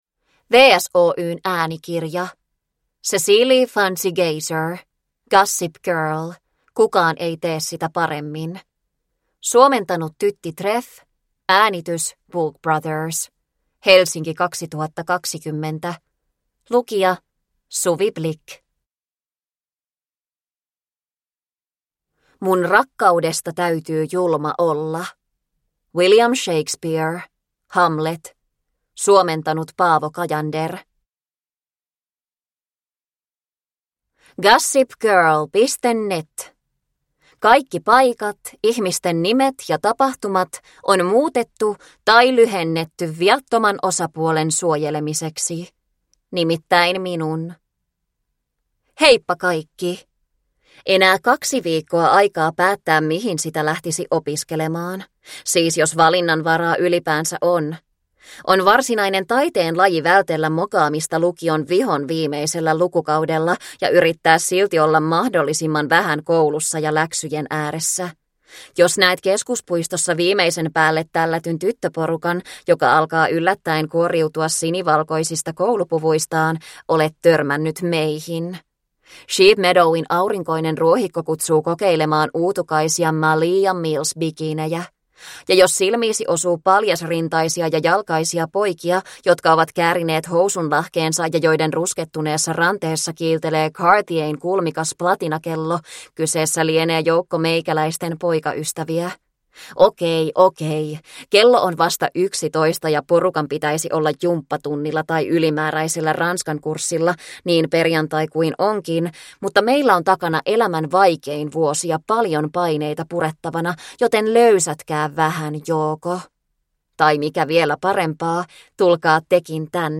Kukaan ei tee sitä paremmin – Ljudbok – Laddas ner